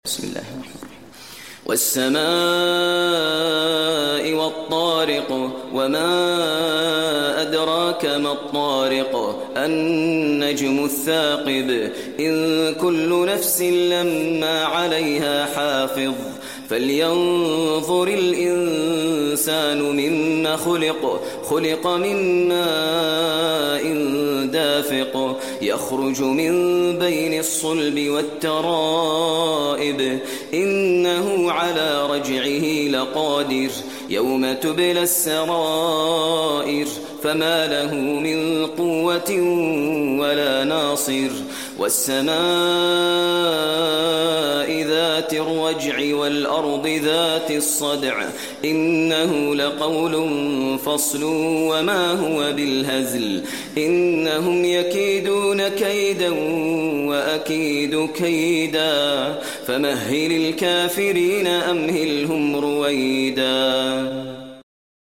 المكان: المسجد النبوي الطارق